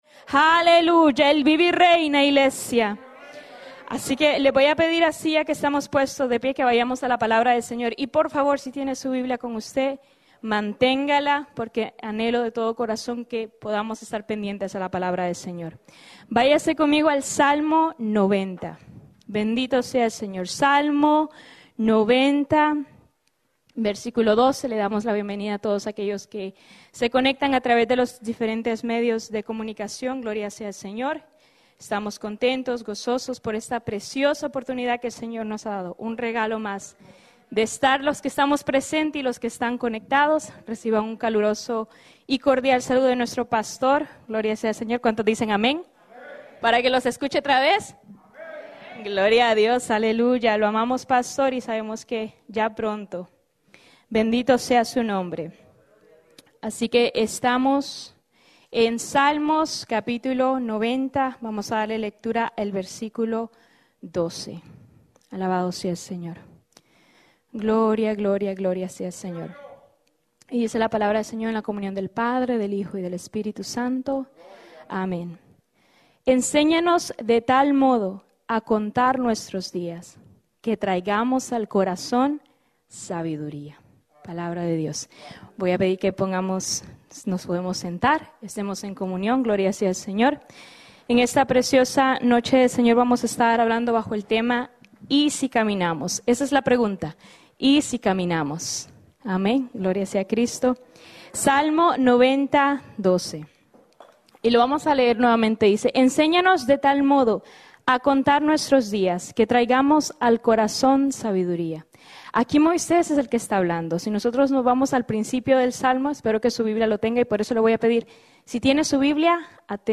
Mensaje
en la Iglesia Misión Evangélica en Souderton, PA